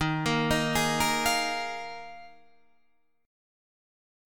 Ebsus2sus4 chord